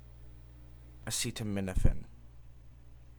Acetaminophen: /əˌstəˈmɪnəfɪn/
En-acetaminophen.oga.mp3